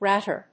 音節rát・ter 発音記号・読み方
/‐ṭɚ(米国英語), ‐tə(英国英語)/